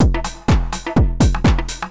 DRUMLOOP162_HOUSE_125_X_SC2.wav